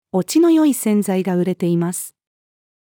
落ちの良い洗剤が売れています。-female.mp3